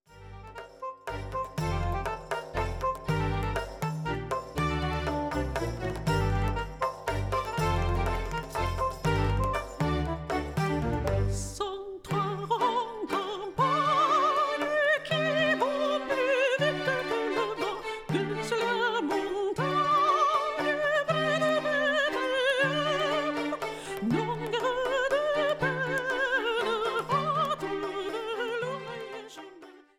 Organisten und Keyboarder
Weihnachtstrio